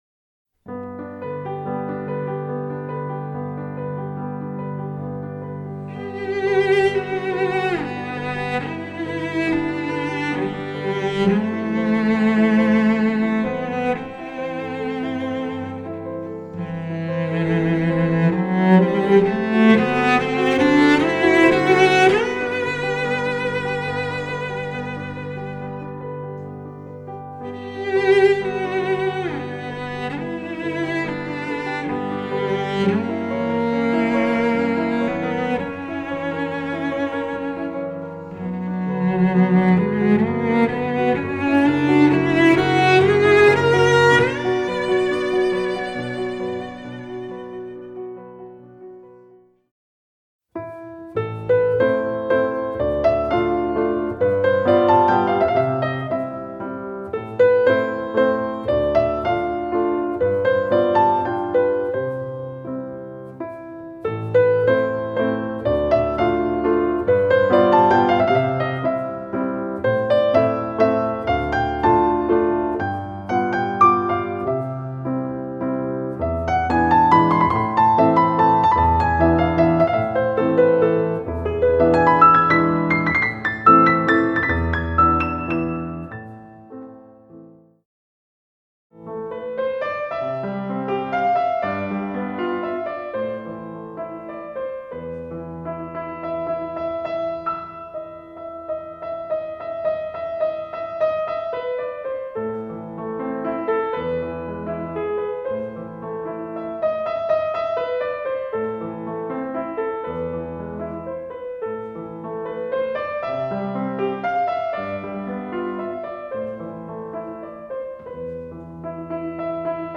Neben sanften Melodien zum Schlummern und Träumen spiegeln springlebendige Stücke unterschiedliche Erlebnisse und Gefühle wieder.
Themenwelt Kunst / Musik / Theater Musik